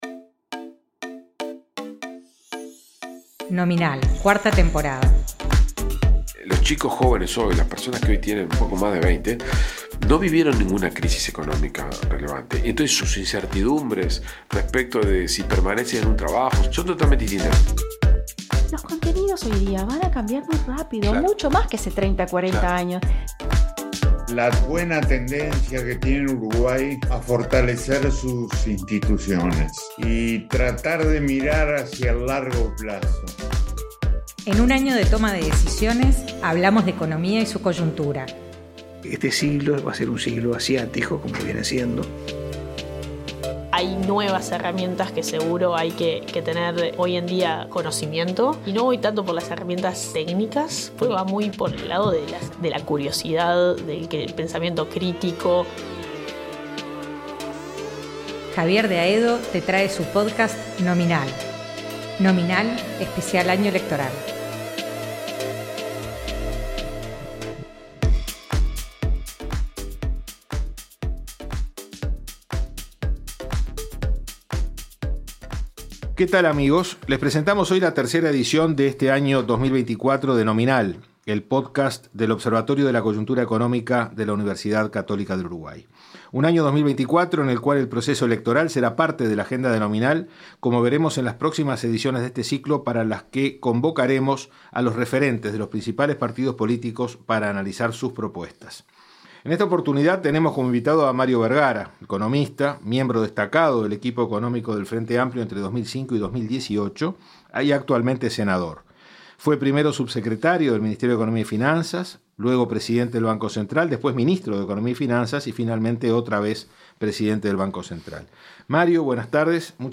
Las elecciones internas están cada vez más cerca y en Nominal calentamos los motores con entrevistas donde conviven la economía y la política.